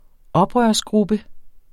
Udtale [ ˈʌbʁɶɐ̯s- ]